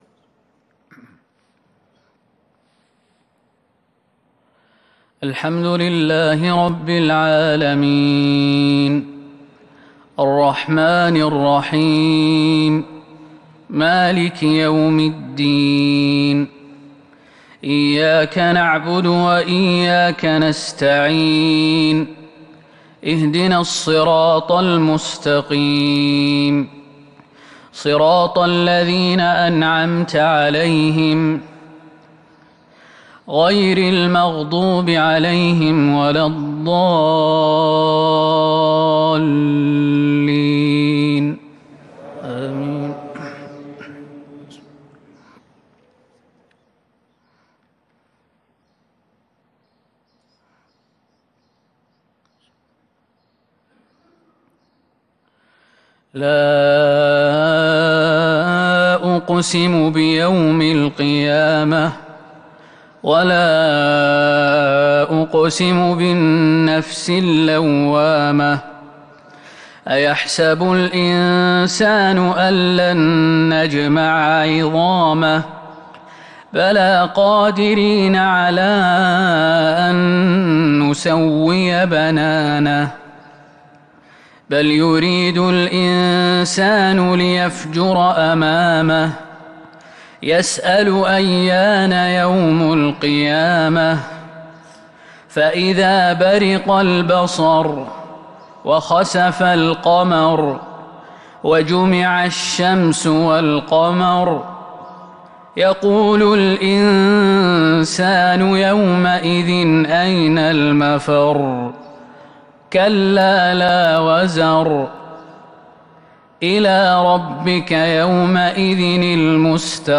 صلاة الفجر من سورتي القيامة و الإنفطار الإثنين 2 ربيع الأول ١٤٤٢ه‍ـ | Fajr prayer from Surat Al-Qiyamah and Surat Al-Infitar 19/10/2020 > 1442 🕌 > الفروض - تلاوات الحرمين